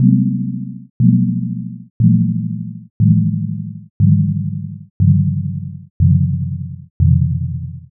In this example, inharmonic overtones are used to create a timpani-like sound.
As well, each overtone can have a different amplitude envelope creating a dynamic timbre that changes over time.
This test code creates a descending chromatic run of eight notes in a low register, as would be typical of a timpani pitch.
TimpaniTest.au